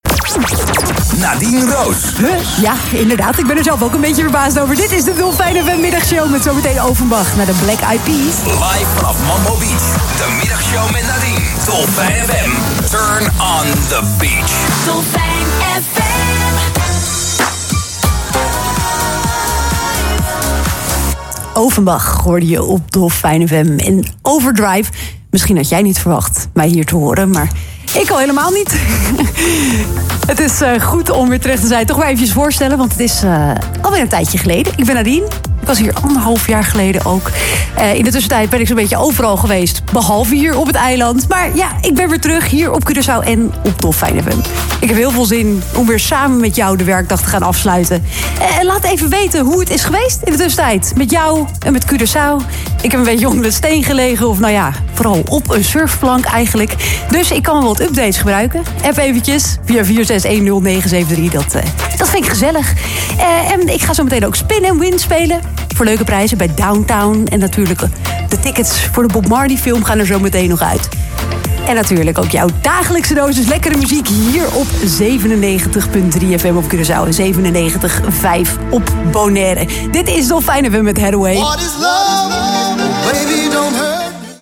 Sinds donderdag presenteert ze weer de dagelijkse middagshow tussen 17 en 19 uur. “Na wereldradiodag en Valentijnsdag ga ik mijn oude liefde weer een kans geven.